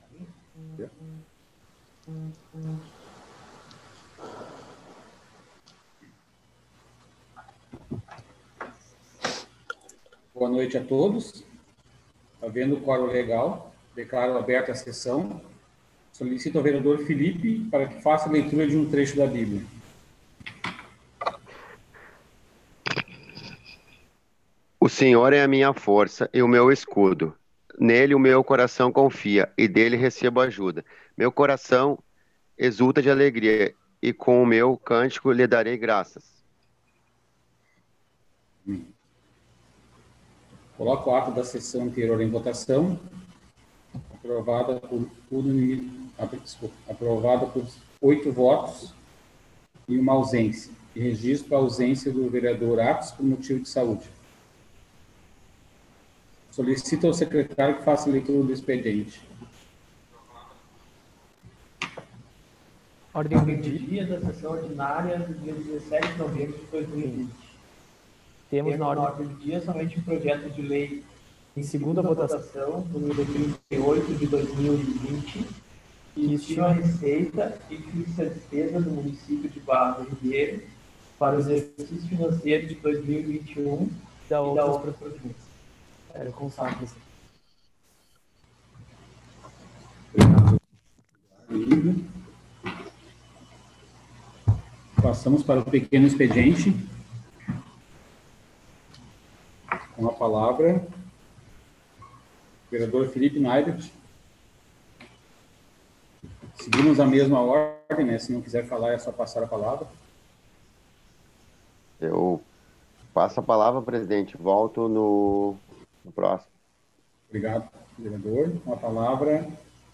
11ª Sessão Online.